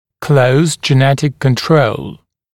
[kləus ʤɪ’netɪk kən’trəul][клоус джи’нэтик кэн’троул]сильное генетическое влияние (на процесс)